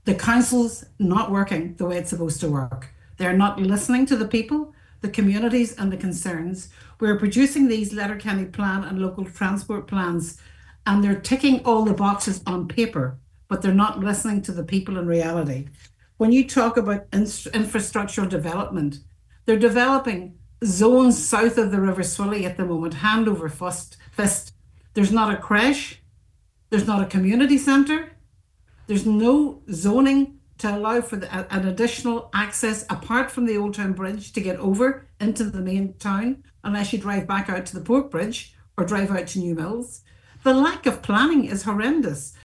on this morning’s Nine ’til Noon Show